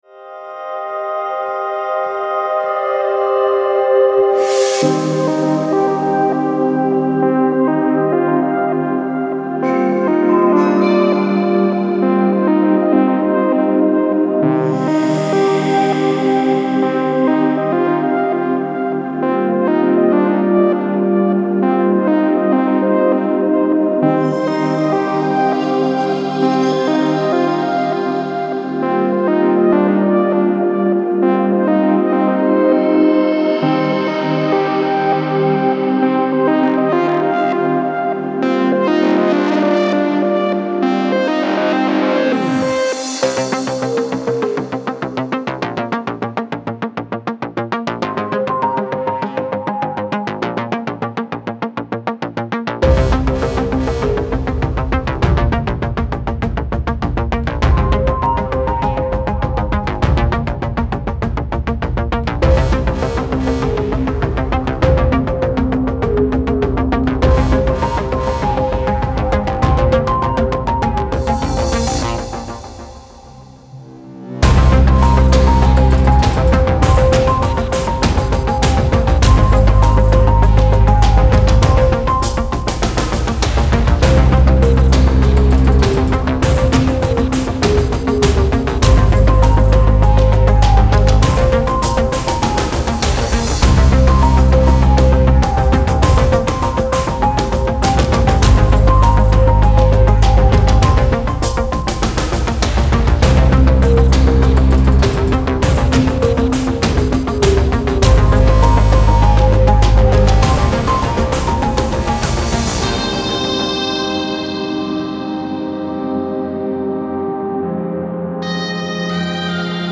Атмосферку апокалипсиса создаёт удивительно он)